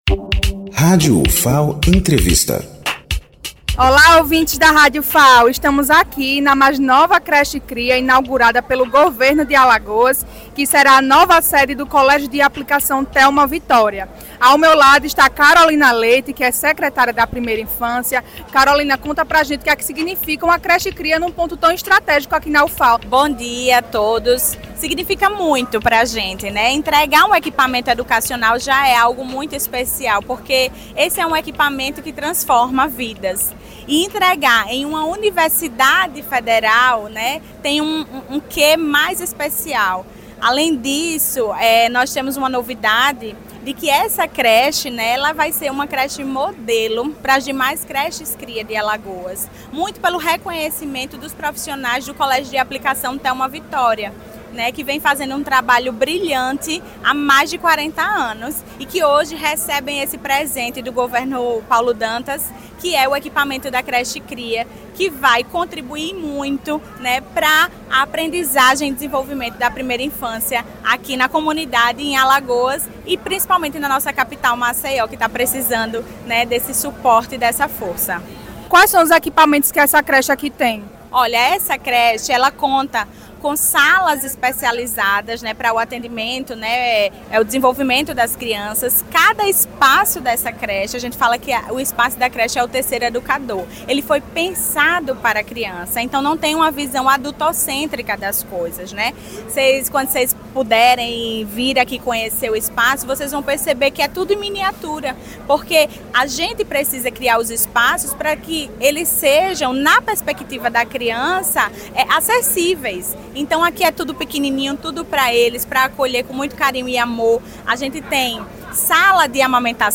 Entrevista com Caroline Leite, secretária da Primeira Infância do Estado de Alagoas